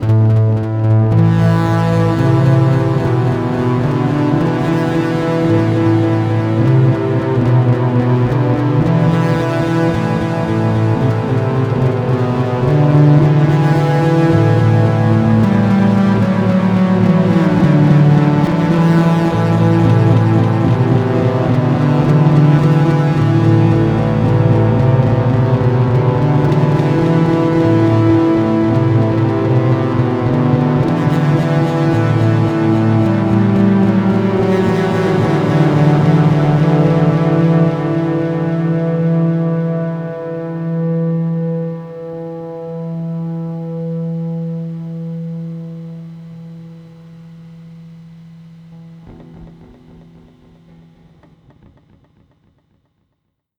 Je to jenom taková krátká blbost, jak udělat skoroklávesy z basy, ale snad to sem patří smile
Basa (teď nevím, jestli jsem měl trochu staženější snímač u krku alá Jaco anebo oba naplno, hráno u hmatníku) + Ibanez BP10(kompresor, plný nastavení big_smile) + Double Muff(mód double naplno) + Digitech Digidelay (mód s klasickým digitálním delayem - na tyhle industriálnější věci někdy vhodnější). V kompu jsem to ořízl o spodek (cca. 28Hz), pak ekvalizoval (boost 100+125+315+630+400 lehce, 800 víc, od 10k a výš o dost) a udělal lehkej fade-out.